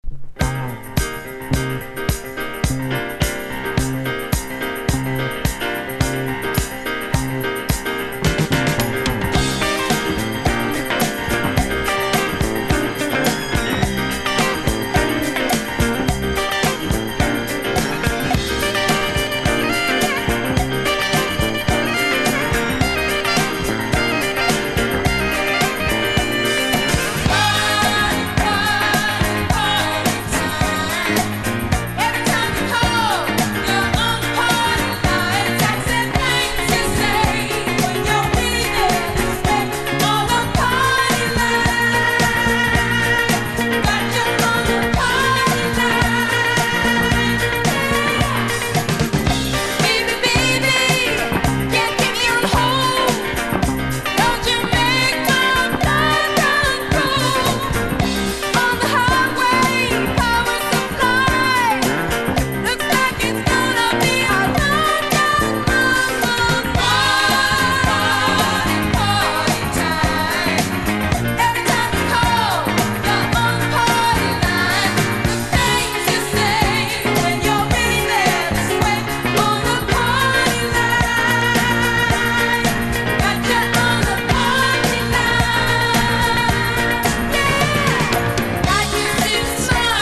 とにかく優しいヴォーカルが最高！
DISCO